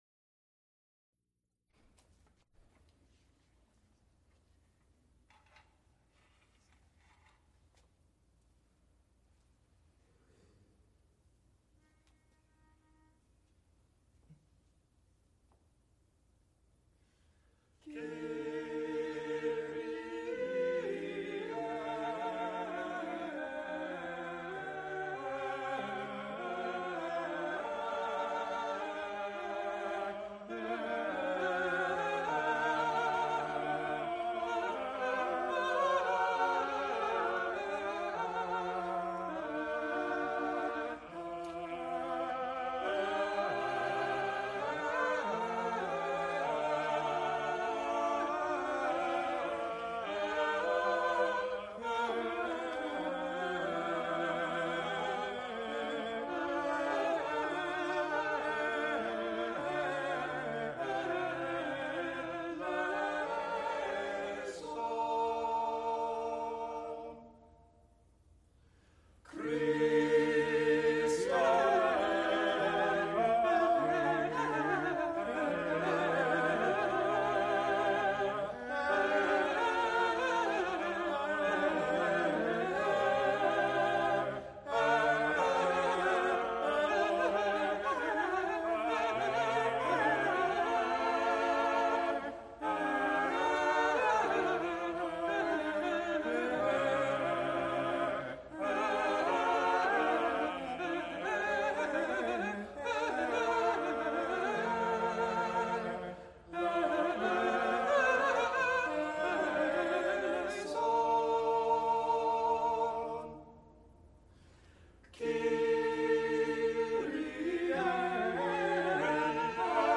Recorded live November 13, 1977, Heinz Chapel, University of Pittsburgh.
Extent 2 audiotape reels : analog, half track, 7 1/2 ips ; 7 in.
Choruses, Secular (Mixed voices), Unaccompanied